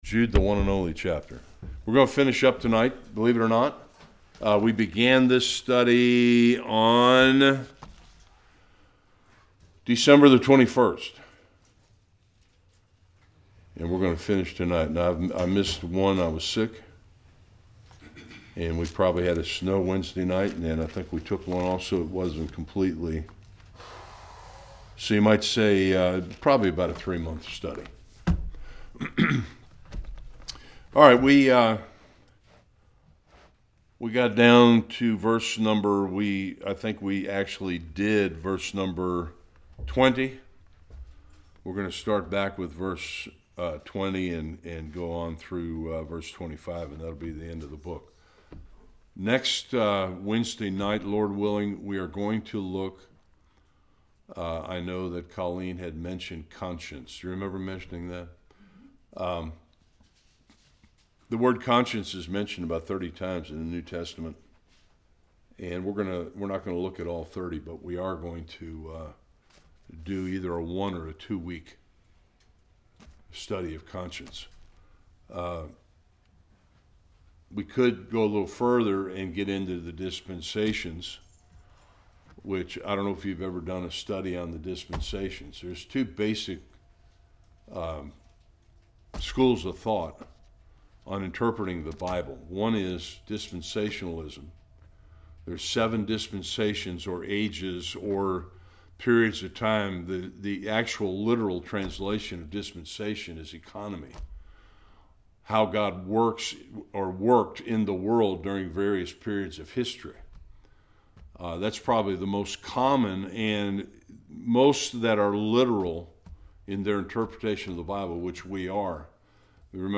Jude 21-25 Service Type: Bible Study Jude closes the letter with a challenge and a beautiful doxology.